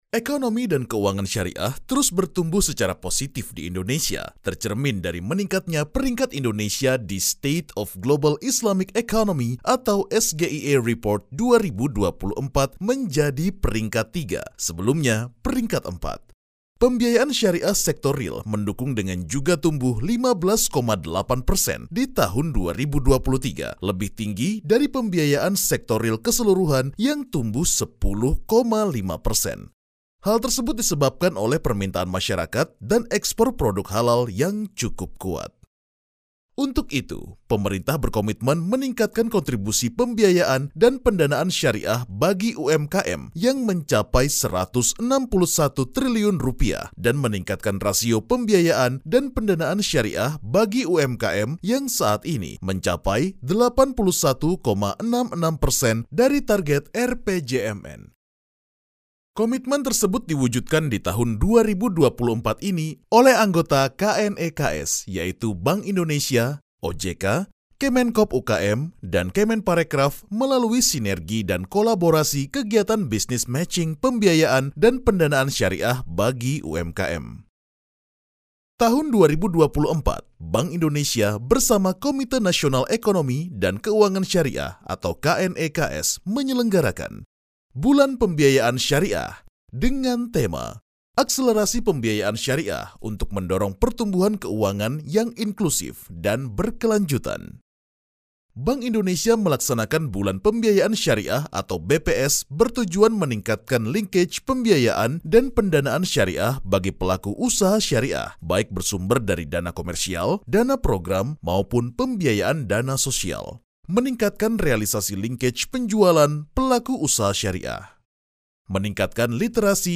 Kommerziell, Cool, Vielseitig, Erwachsene, Warm
Erklärvideo